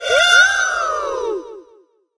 tara_hurt_vo_01.ogg